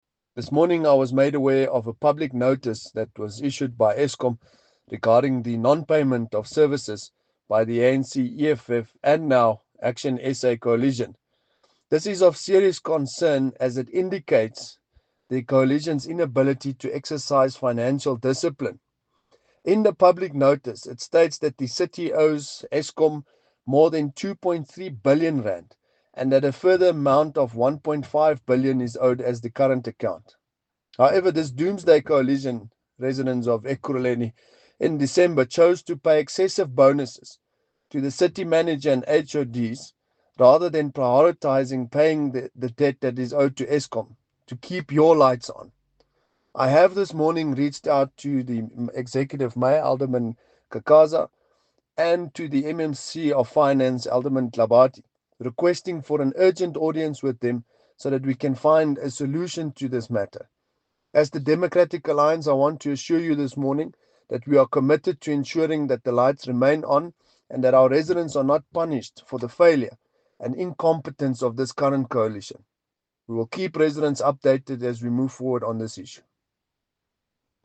Note to Editors: Please find an English soundbite by Cllr Brandon Pretorius